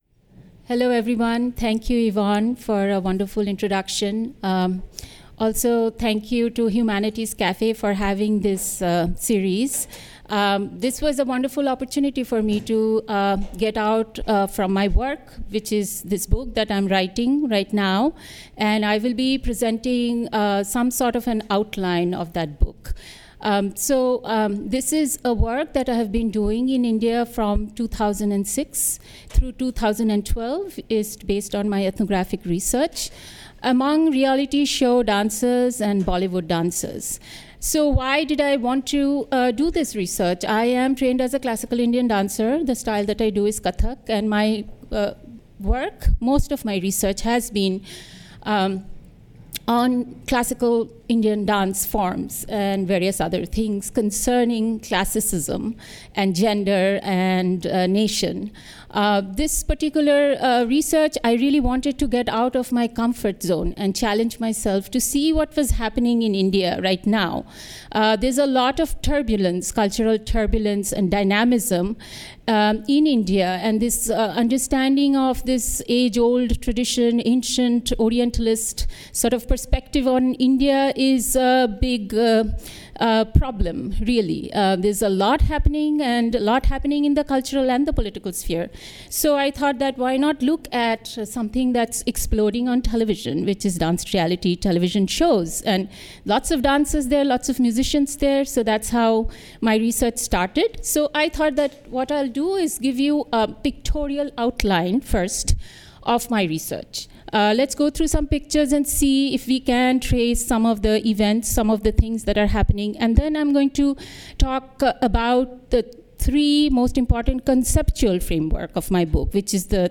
discusses her current ethnographic research on reality show and Bollywood dancers as part of the Second Tuesday Arts & Humanities Cafe series.